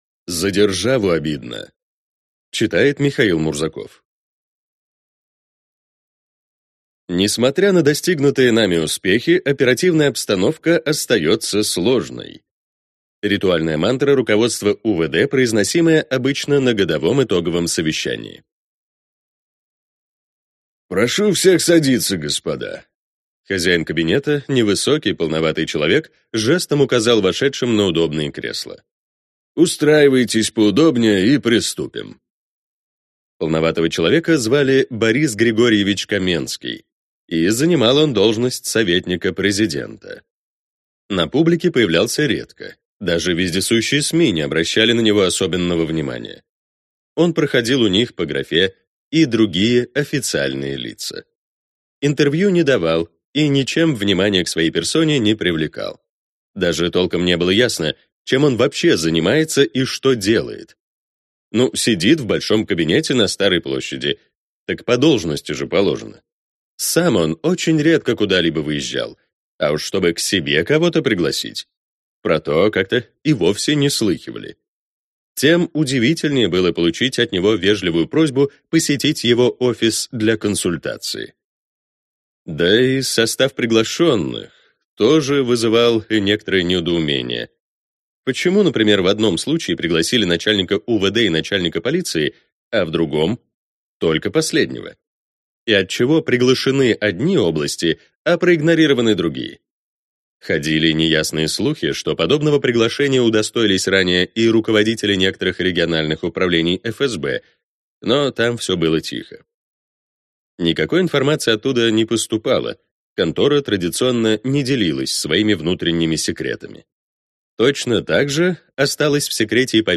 Аудиокнига За Державу обидно!